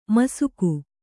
♪ masuku